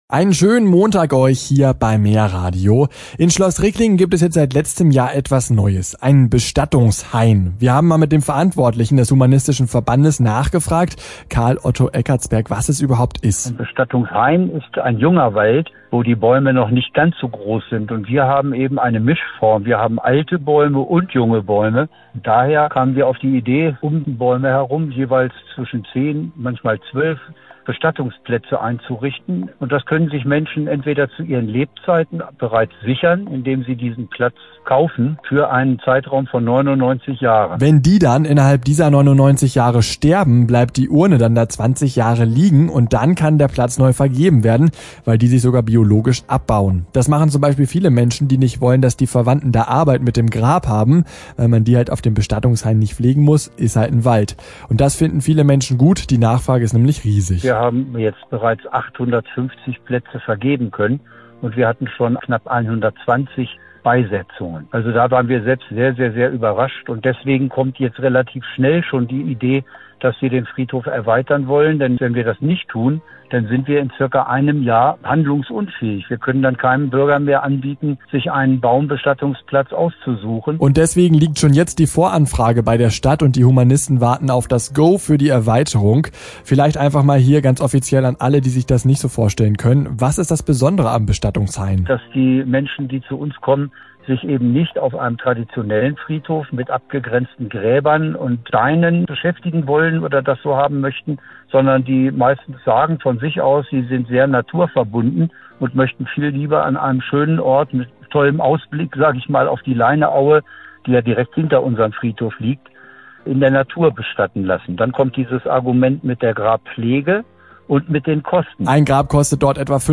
Unsere Leineaue im Radiobeitrag